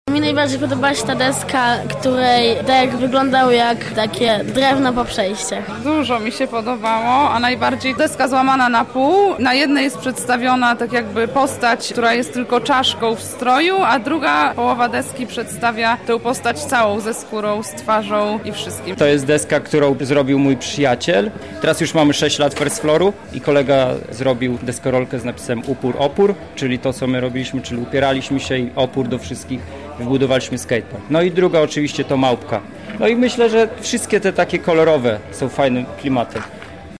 Za nami wernisaż wystawy deskorolkowego graffiti.